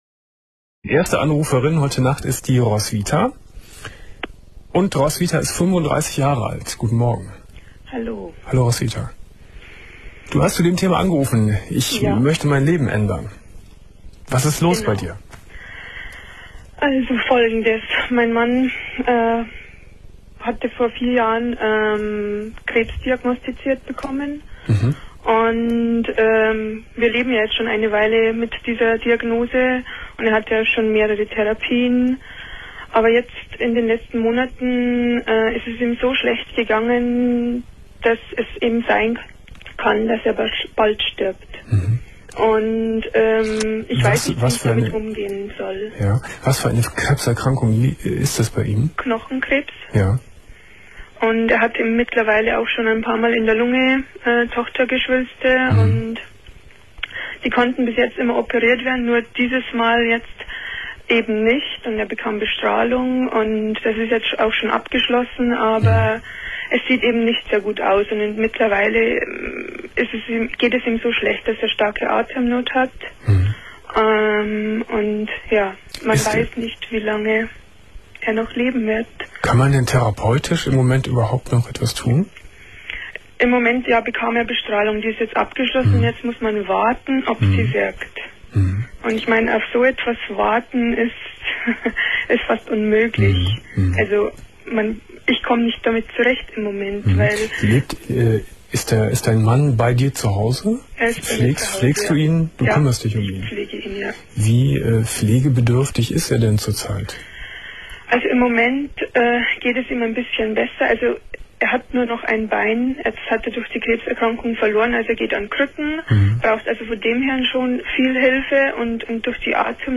Domian Talkradio